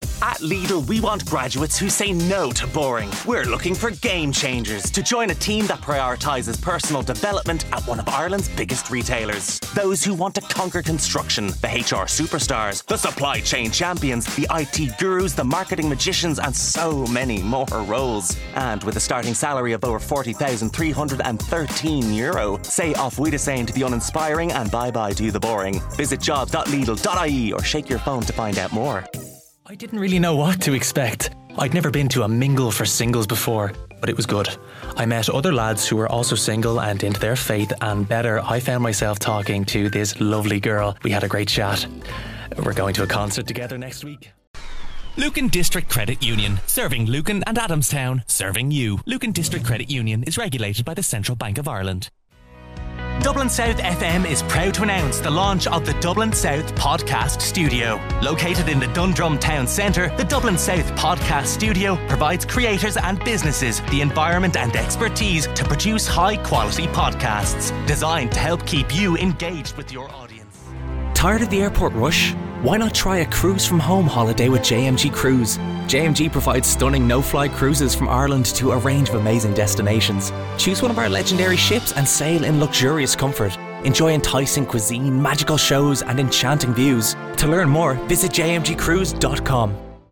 Male
Audiobox USB Interface 96, Adobe Audition, Thronmax Mdrill Zone,
20s/30s, 30s/40s
Irish Dublin Neutral, Irish Neutral